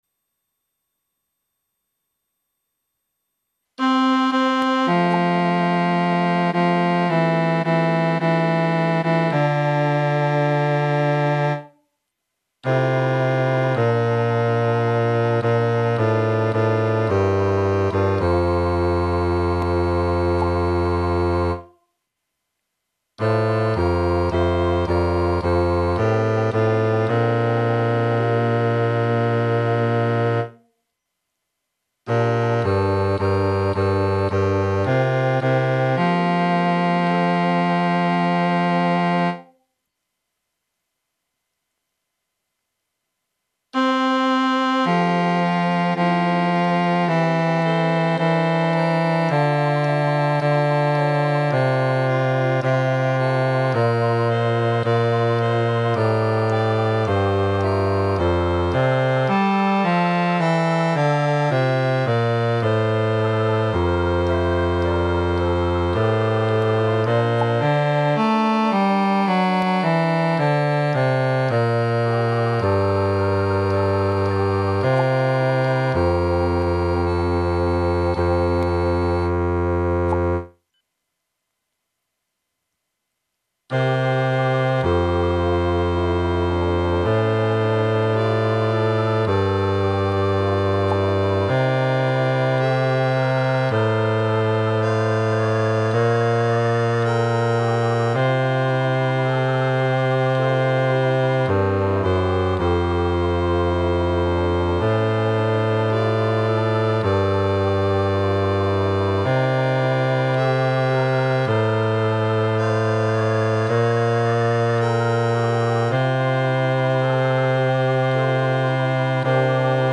vierstemmig gemengd zangkoor